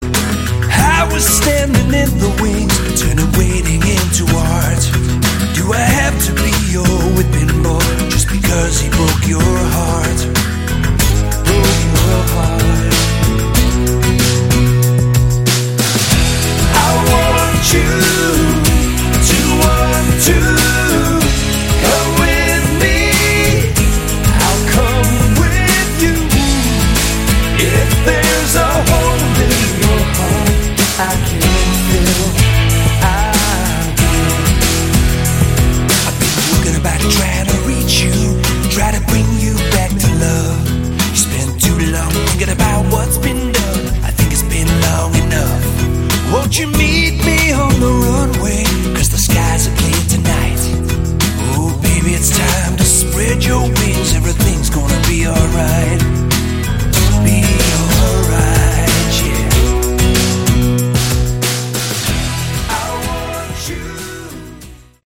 Category: Westcoast AOR
vocals, guitars
bass, vocals